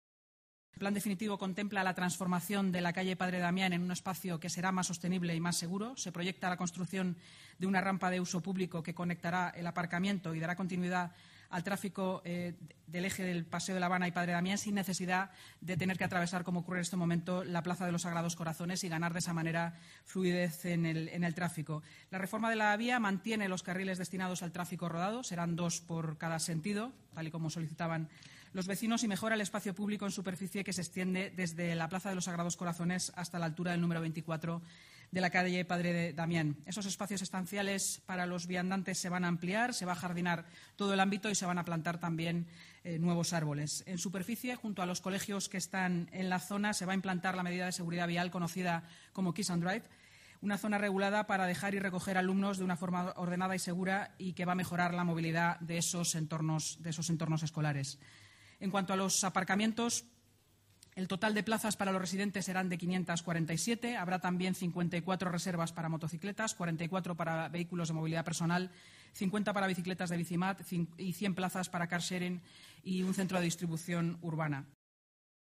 Nueva ventana:Declaraciones de la portavoz municipal, Inmaculada Sanz